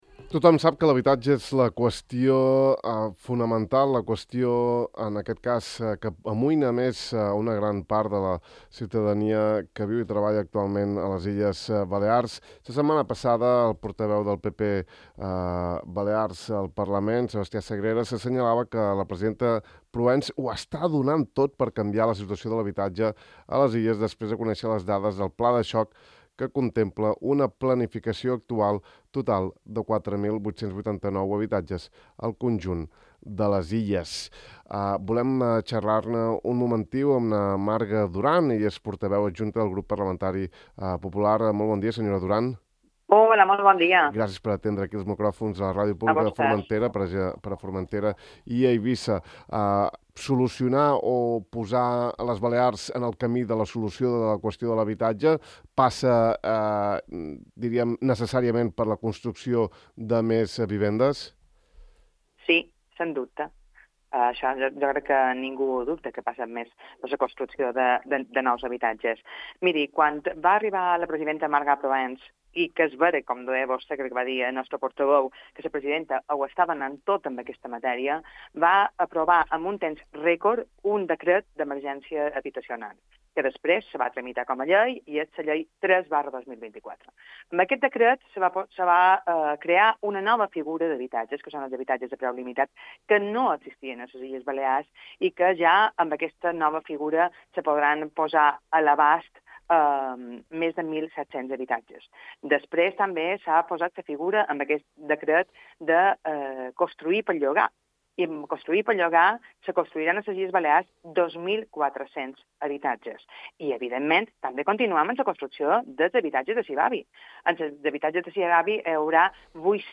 Marga Duran, portaveu adjunta del grup parlamentari Popular, ha afirmat a Ràdio Illa que les mesures del Govern actual possibilitaran la creació de 15 nous habitatges.